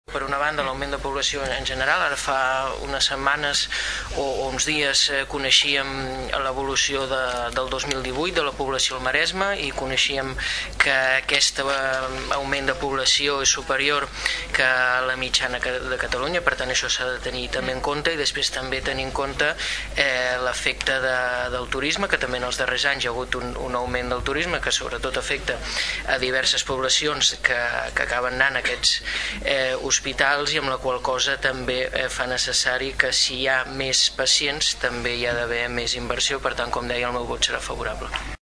Per part del PP, Xavi Martin reforçava el vot favorable argumentant que l’augment de població viscuda al Maresme i Tordera, a més de l’augment del Turisme, impliquen la millora i inversió en el centre sanitari.